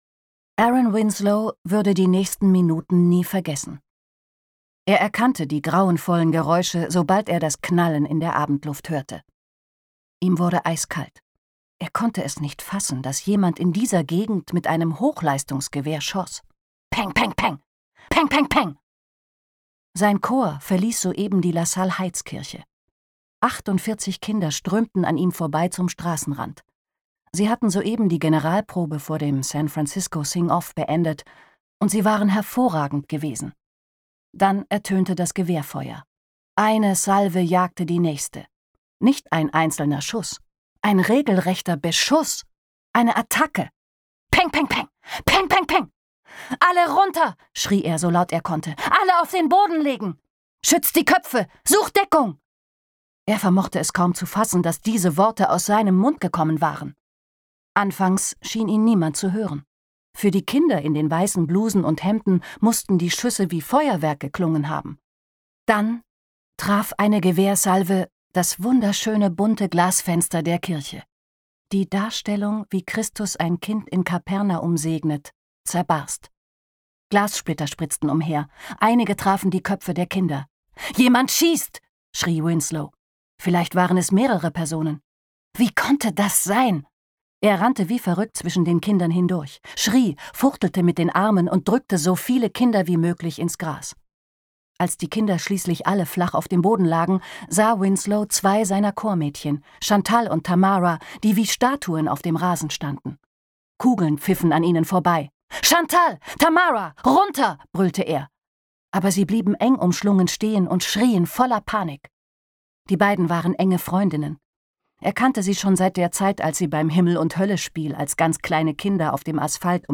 Hörbuch: Die 2.